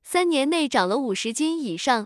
tts_result_4.wav